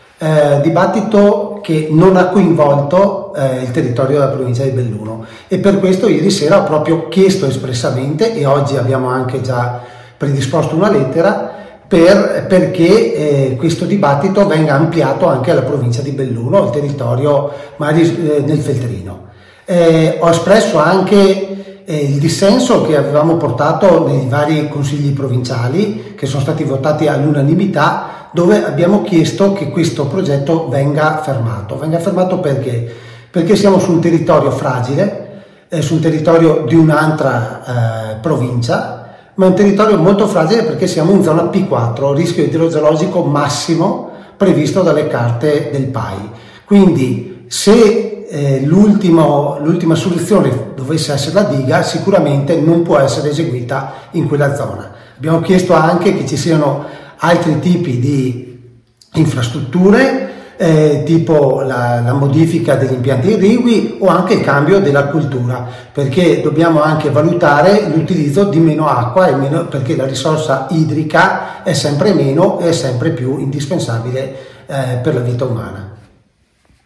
il consigliere provinciale Massimo Bortoluzzi
bortoluzzi-intervento.mp3